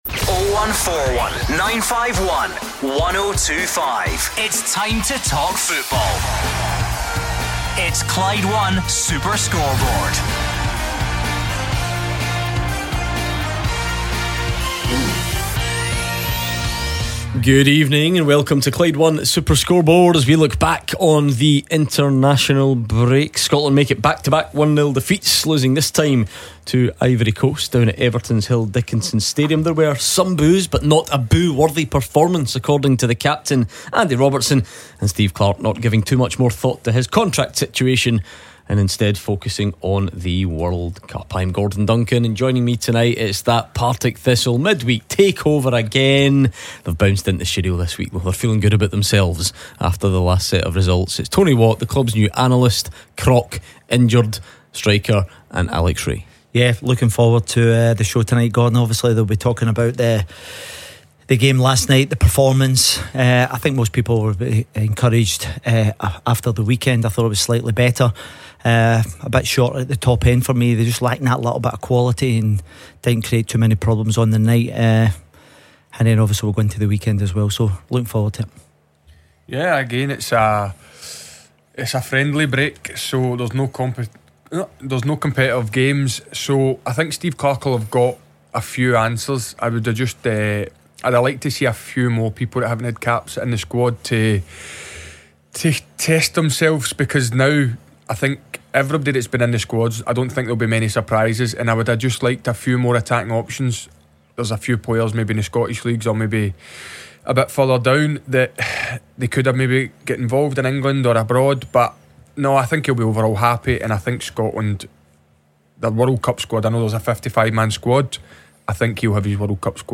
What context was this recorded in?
bring you live coverage of the Champions League knockout round keeping you up to date from the Allianz Arena and half time reaction from you